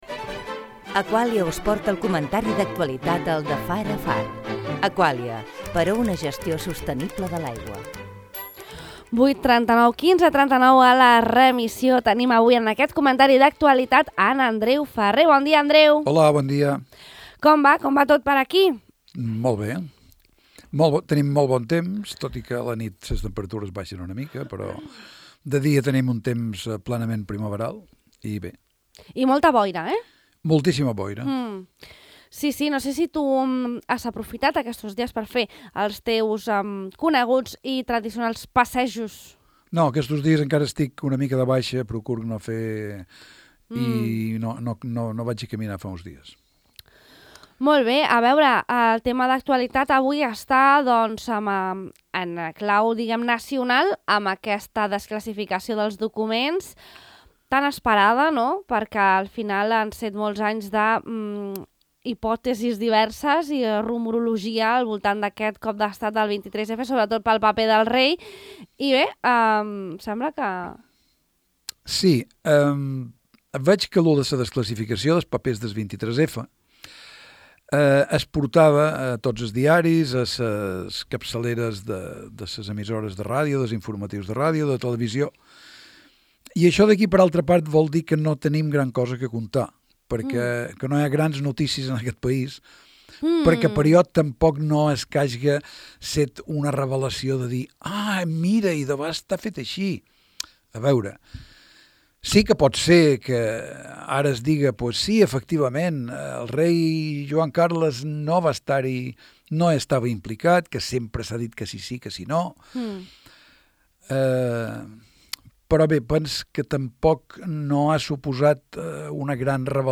Comentari d’actualitat
L’any en estampes D’altra banda, el convidat ha llegit un fragment del llibre ‘L’any en estampes’, de Marià Villangómez, corresponent al mes de febrer.